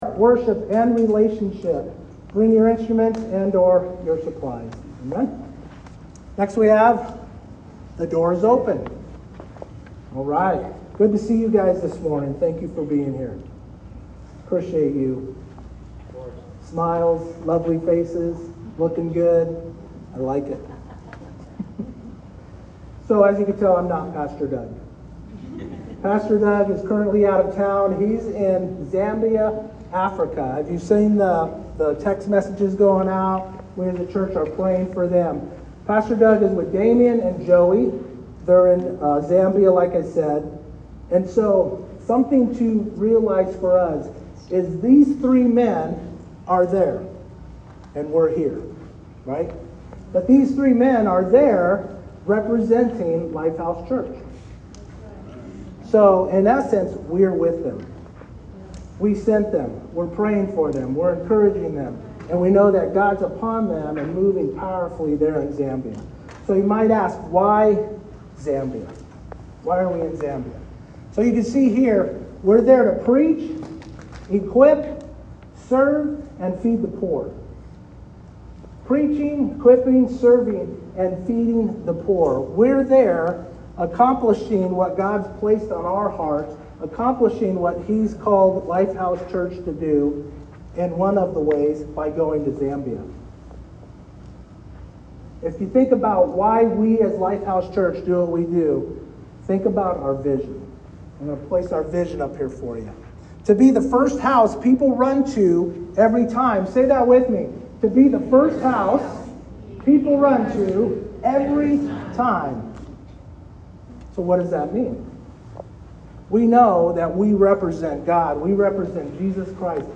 LifeHouse Church – Sunday at 10AM Come expectant.